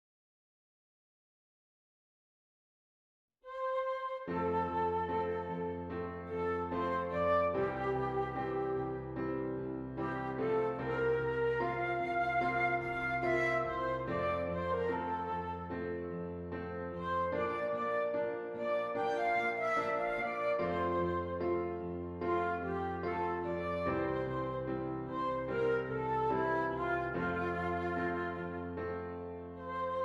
Flute Solo with Piano Accompaniment
F Major
Lento